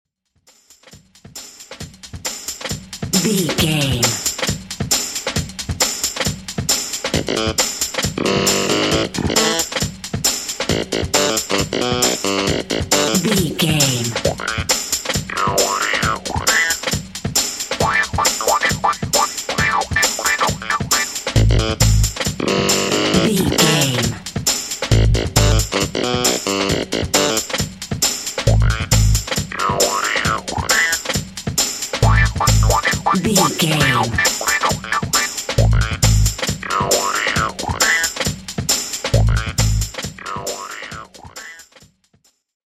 Aeolian/Minor
electronic
new age
techno
trance
synths
glitch